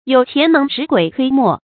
注音：ㄧㄡˇ ㄑㄧㄢˊ ㄣㄥˊ ㄕㄧˇ ㄍㄨㄟˇ ㄊㄨㄟ ㄇㄛˋ